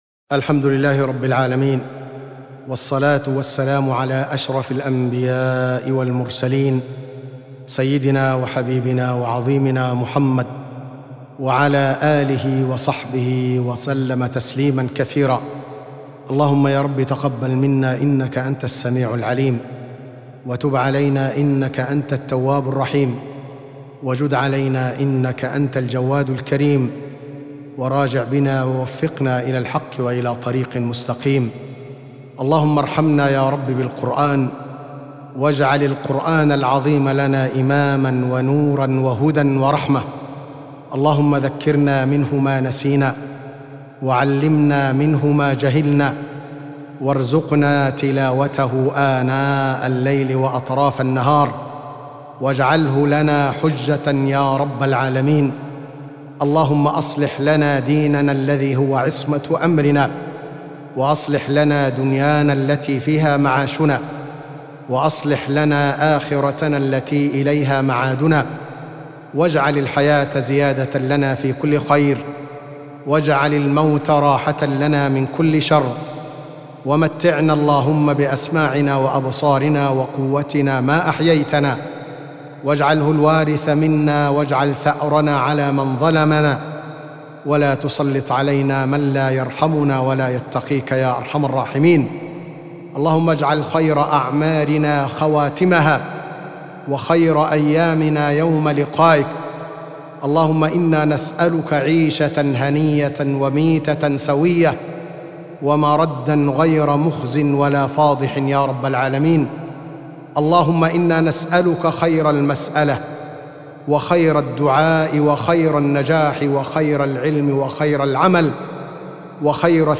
أدعية وأذكار
تسجيل لدعاء ختم المصحف المرتل برواية حفص